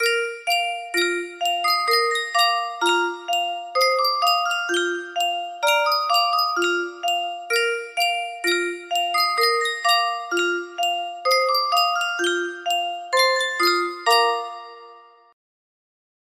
Sankyo Music Box - Croatia National Anthem MBE music box melody
Full range 60